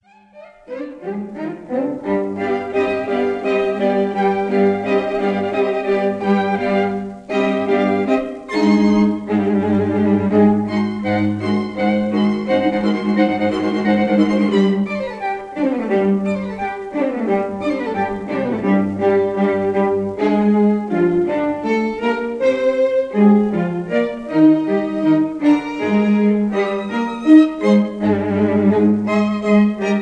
violins
viola
cello
in C minor — Presto e con tutta la forza